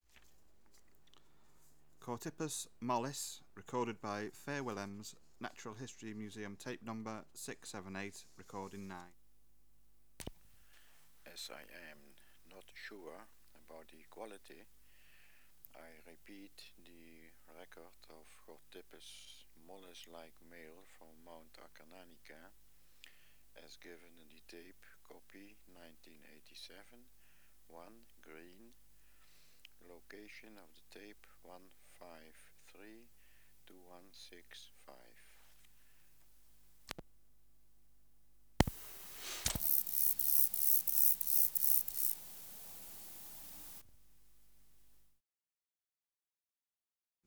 Species: Chorthippus (Glyptobothrus) mollis
Recording Location: Room in private house. Eygelshoven, Netherlands.
Substrate/Cage: In cage Biotic Factors / Experimental Conditions: Isolated male
Microphone & Power Supply: AKG D202E (LF circuit off) Distance from Subject (cm): 10